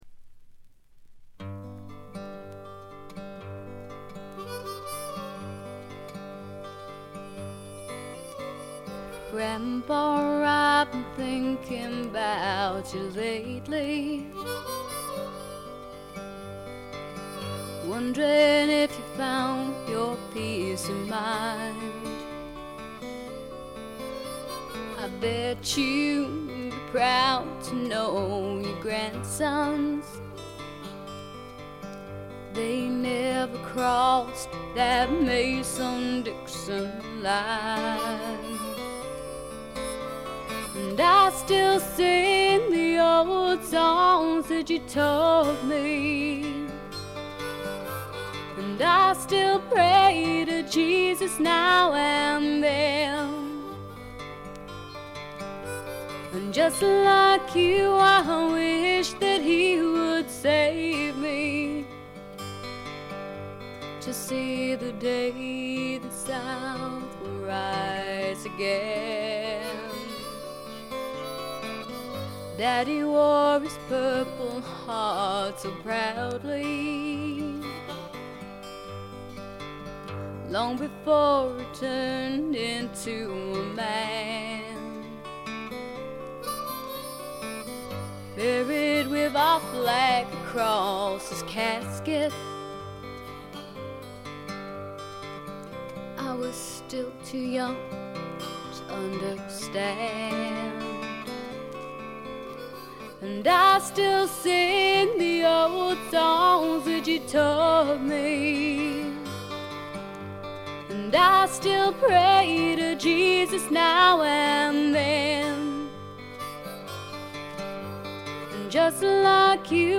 イングランドの小さなレーベルに残された女性シンガーの好盤です。
試聴曲は現品からの取り込み音源です。